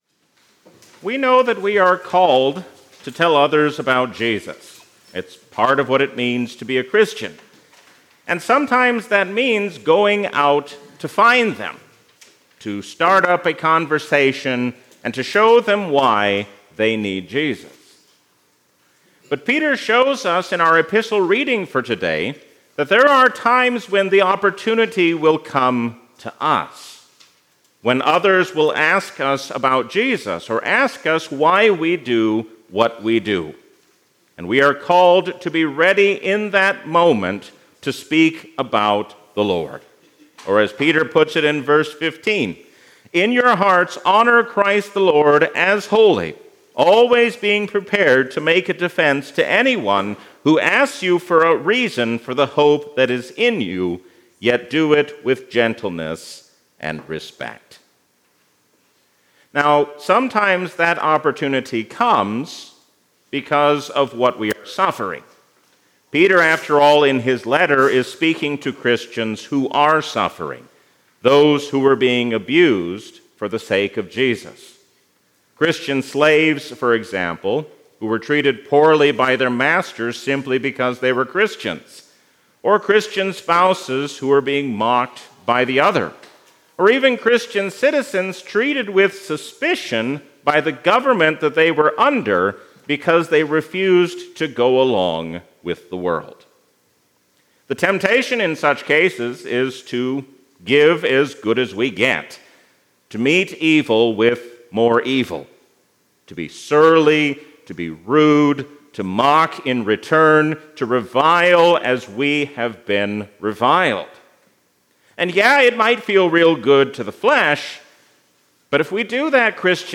A sermon from the season "Trinity 2025."